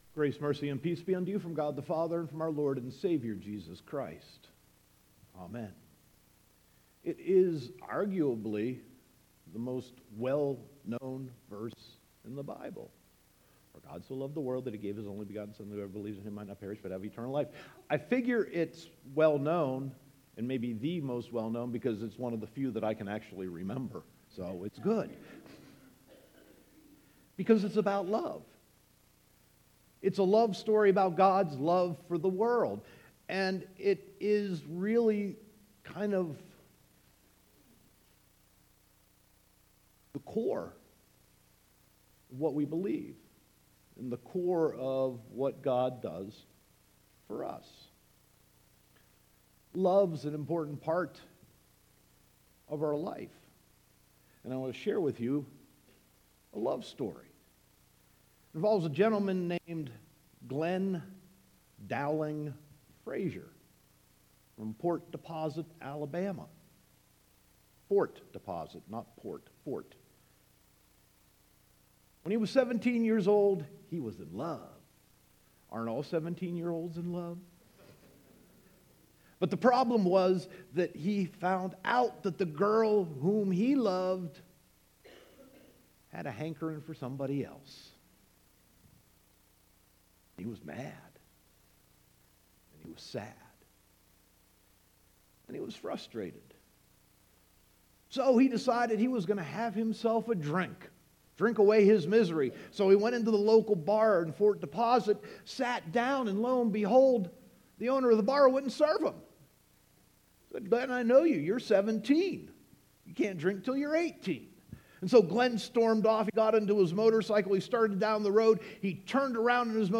Sermon 3.11.2018